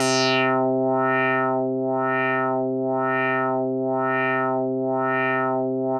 Index of /90_sSampleCDs/Trance_Explosion_Vol1/Instrument Multi-samples/LFO Synth
C4_lfo_synth.wav